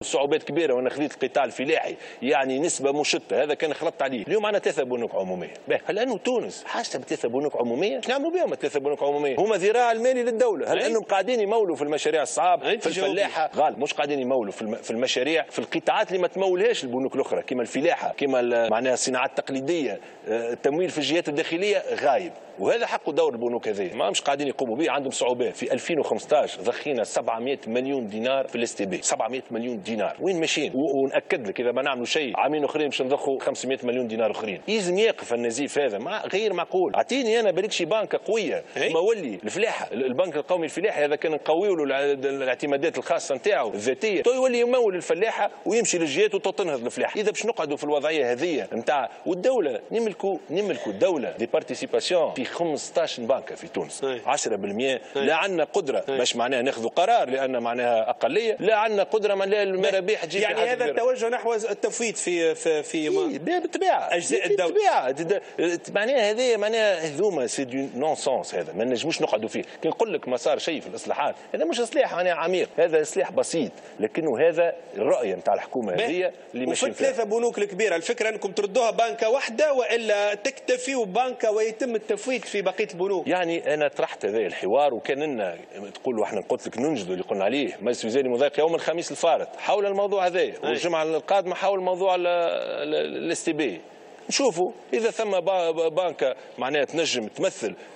قال رئيس الحكومة يوسف الشاهد في حوار خاص اليوم الأحد إن تونس ليست في حاجة ل3 بنوك عمومية ولذلك يجب التفويت فيها وخوصصتها.